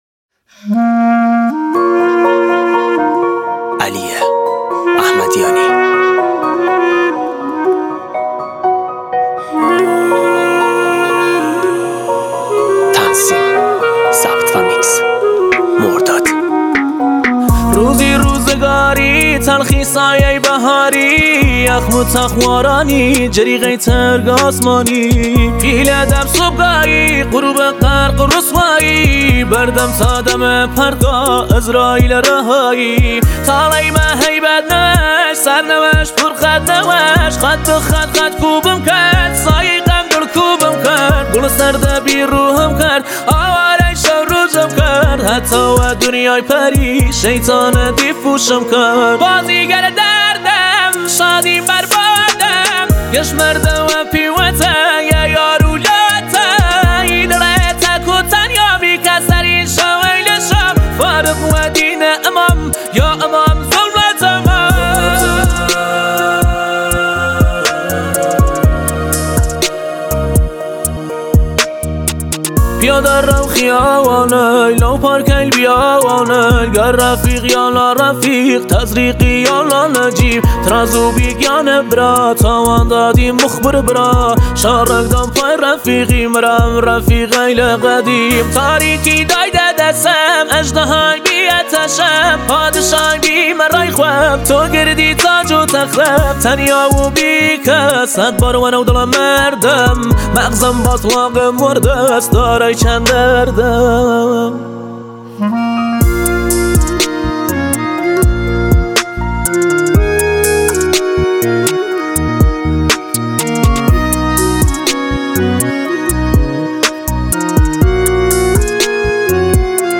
هیپ هاپ